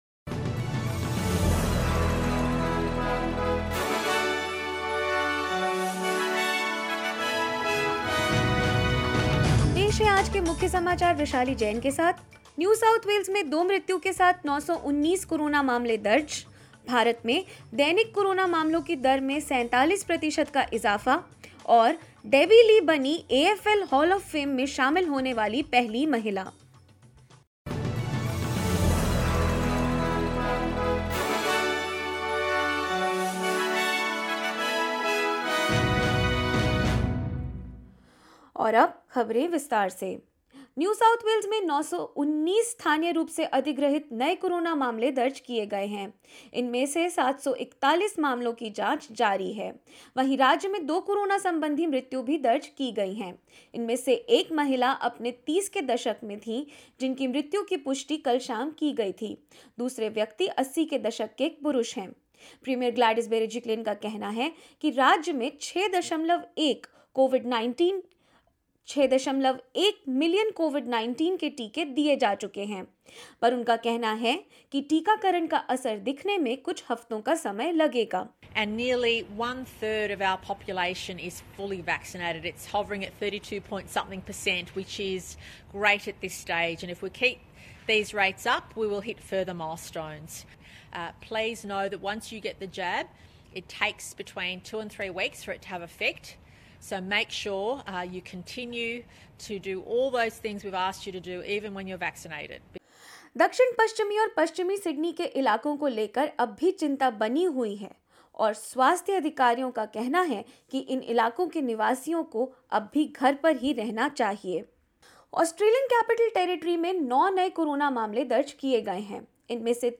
In this latest SBS Hindi News Bulletin of Australia and India: NSW records 919 daily cases and two deaths; National Treasury is hopeful of Australian economy will recover December quarter as vaccination rates go up and more.